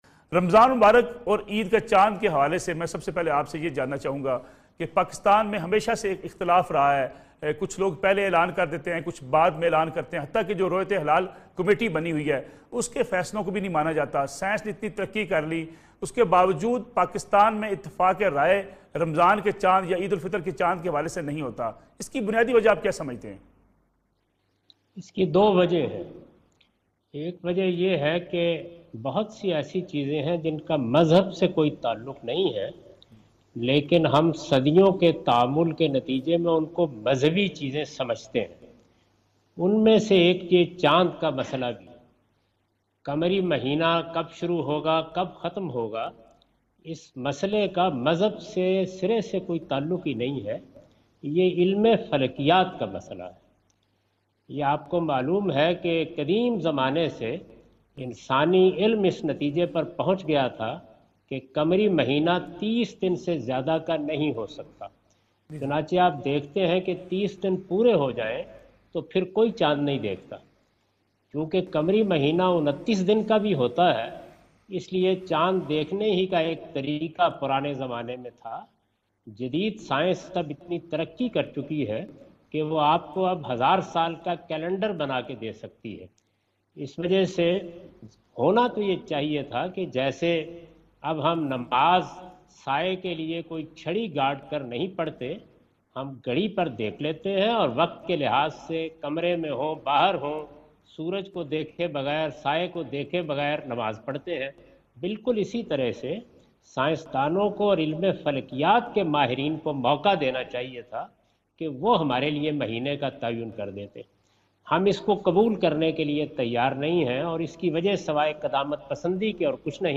Category: TV Programs / Neo News /
In this program Javed Ahmad Ghamidi answer the question about "Issue of Moon Sighting in Pakistan" on Neo News.
جاوید احمد غامدی نیو نیوزکے پروگرام میں "پاکستان میں رویت ہلال کا مسئلہ "سے متعلق سوال کا جواب دے رہے ہیں۔